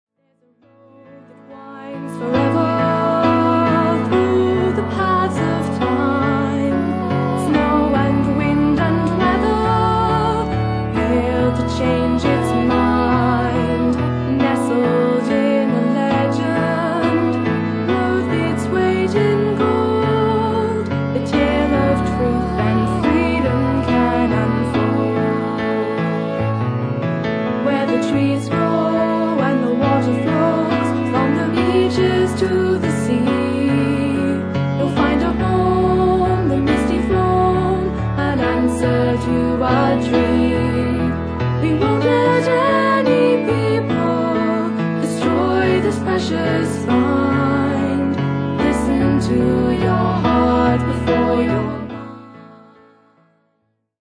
who played Celtic, French-Canadian and original music